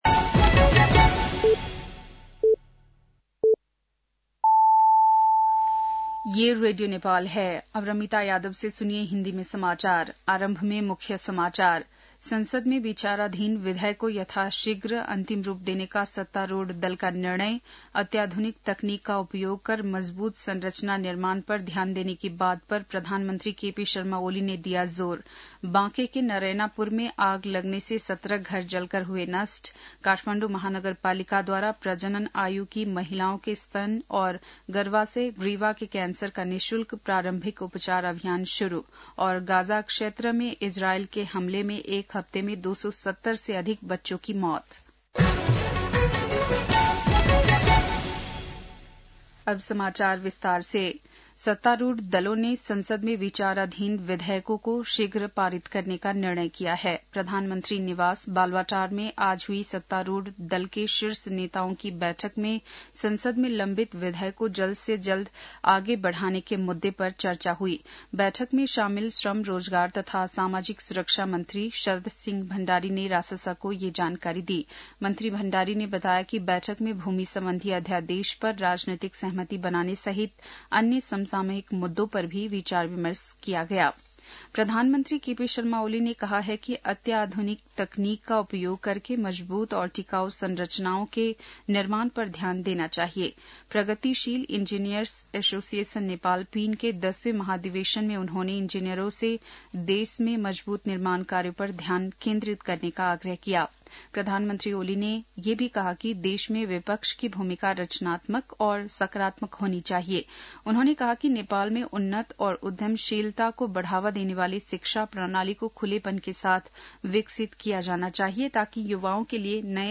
बेलुकी १० बजेको हिन्दी समाचार : १२ चैत , २०८१